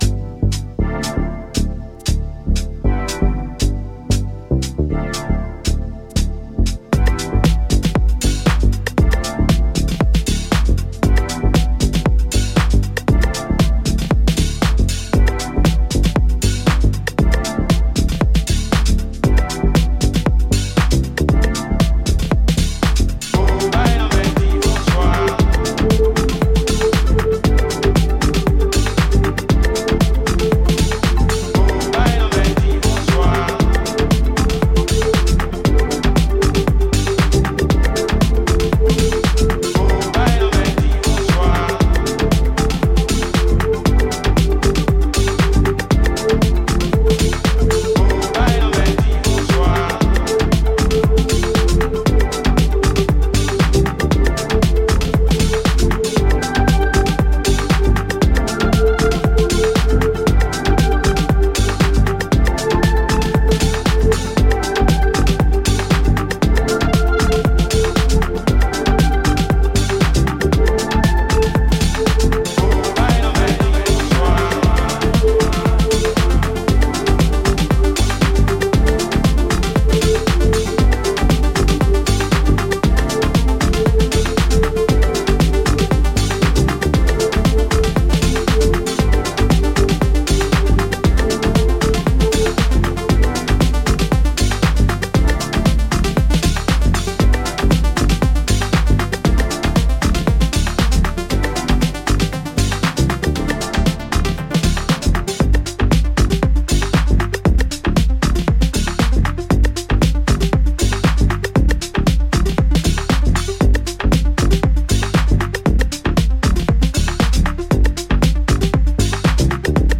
give the track a more Afro cosmic and dance-floor appeal.